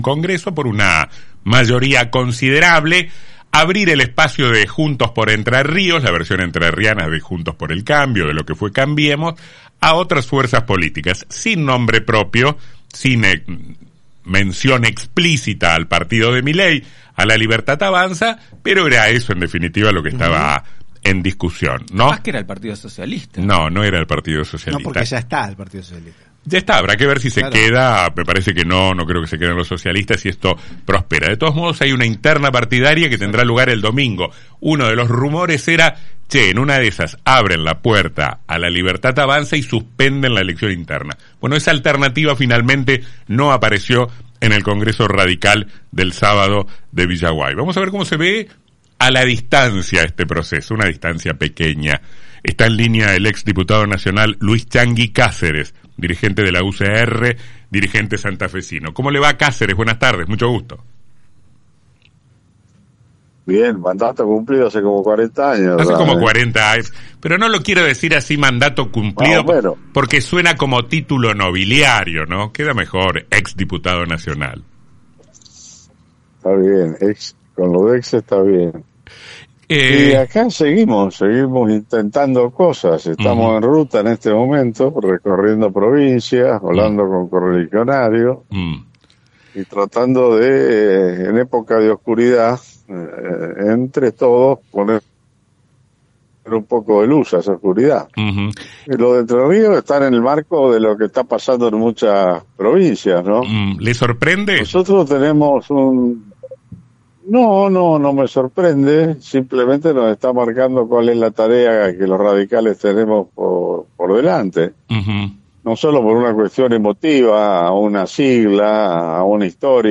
entrevista
Changui-Caceres.mp3